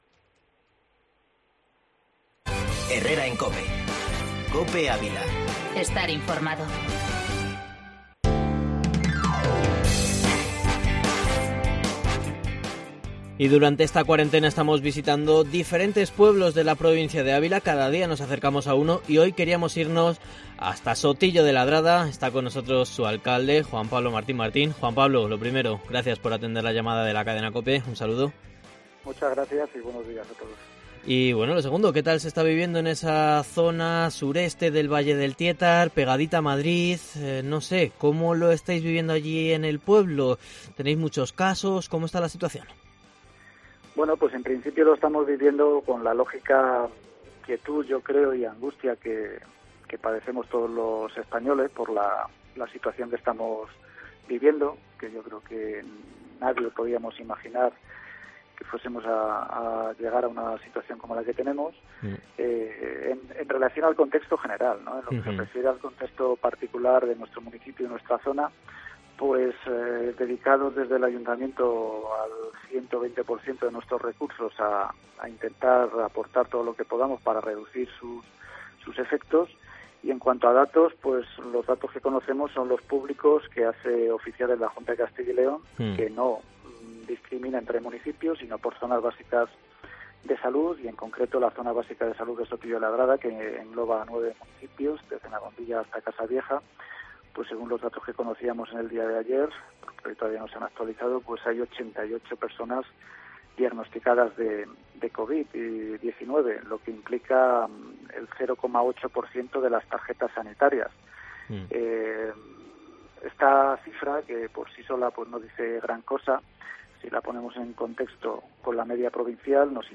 Entrevista en COPE Ávila a Juan Pablo Martín, alcalde de Sotillo de La Adrada.